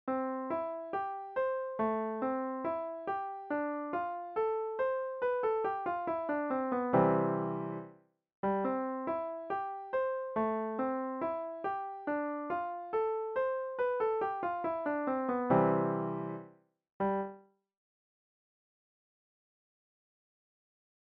Here's a four measure lick, arpeggiating diatonic chords, ending on beat one.
Feel the direction, tension and resolution of this phrase?